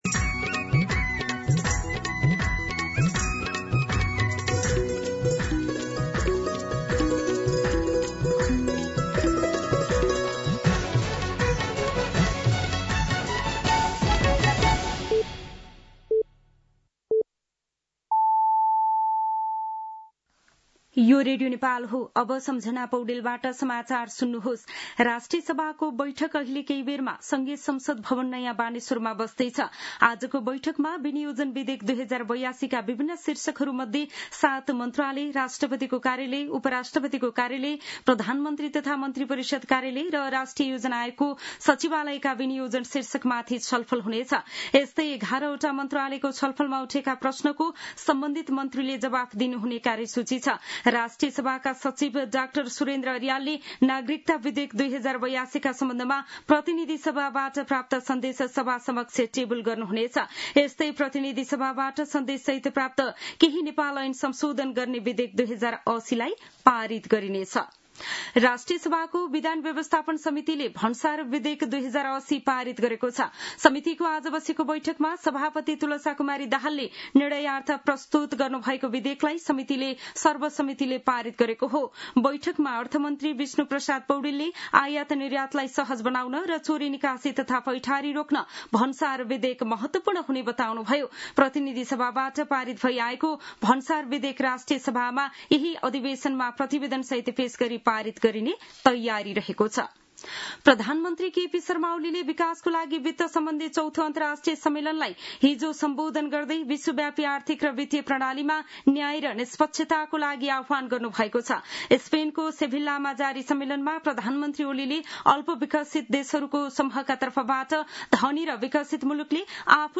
मध्यान्ह १२ बजेको नेपाली समाचार : १७ असार , २०८२